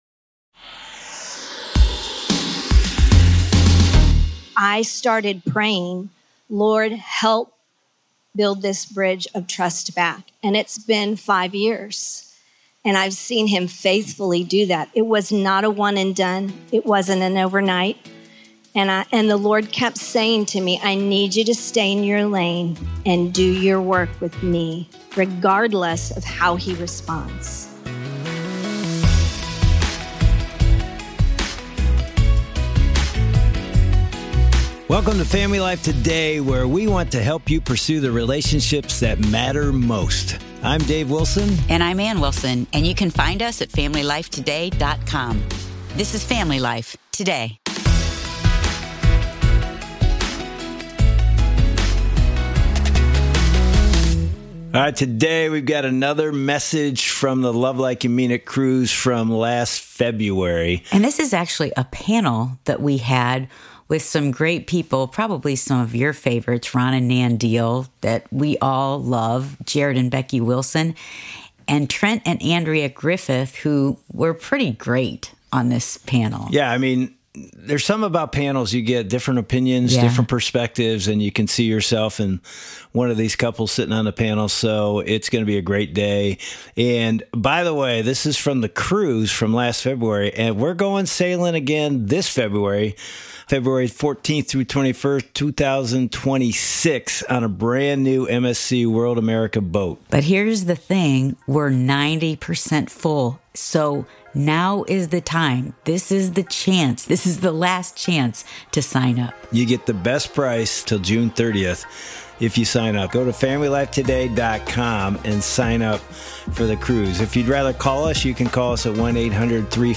Ever wonder how to navigate tricky in-law dynamics, prepare for the quiet of an empty nest, or truly "leave and cleave" in your marriage? Join a candid and insightful conversation from the 2025 Love Like You Mean It Marriage Cruise panel